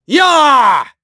Siegfried-Vox_Attack3.wav